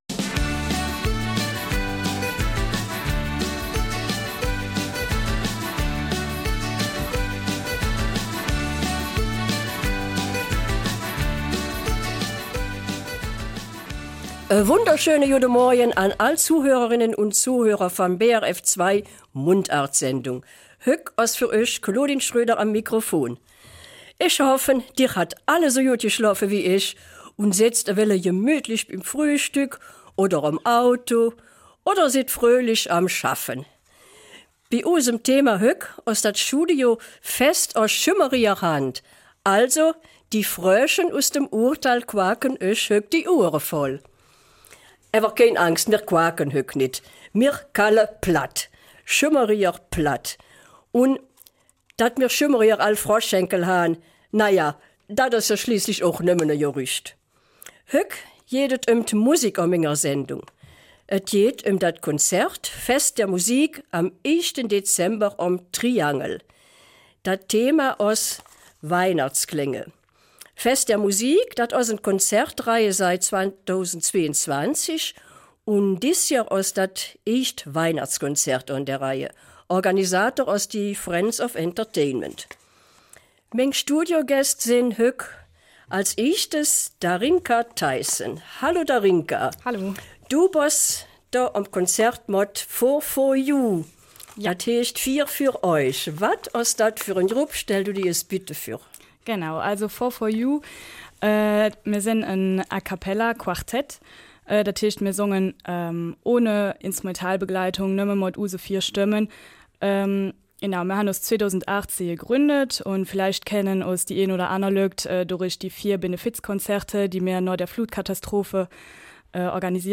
Eifeler Mundart - 17. November